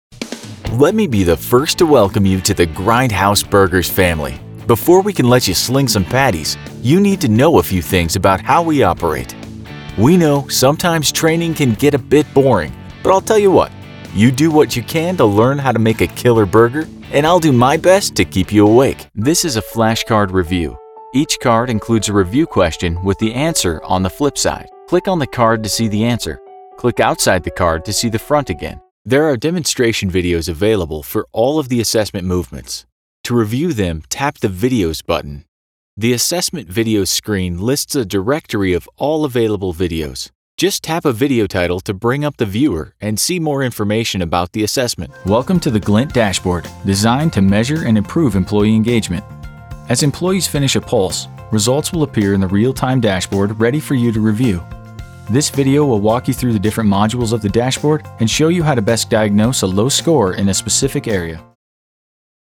Young Adult, Adult Has Own Studio
Location: Billings, MT, USA Languages: english Accents: standard us | natural Voice Filters: COMMERCIAL FILTER warm/friendly NARRATION FILTERS e-learning explainer video